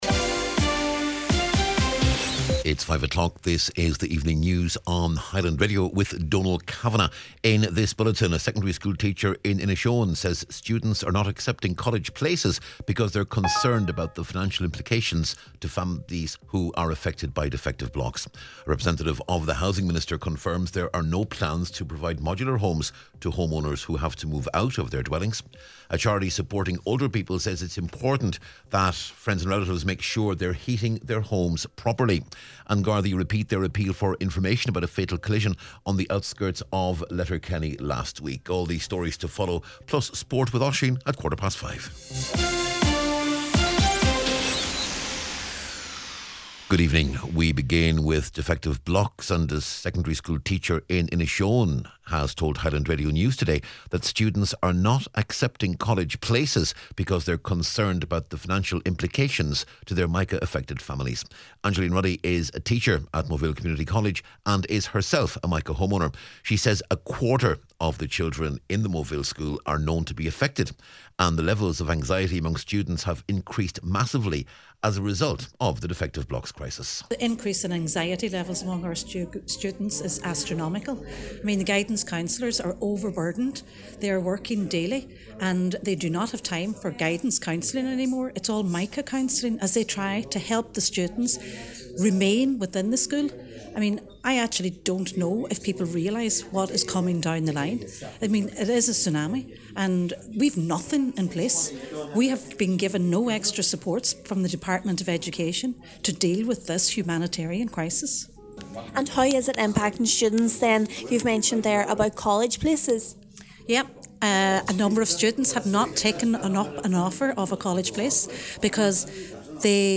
News, Sport and Obituary Notices on Tuesday November 15th